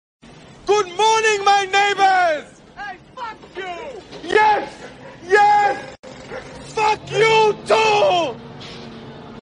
It’s chill and fun and relaxed. The guitar keyboard is awesome as is the rhythm.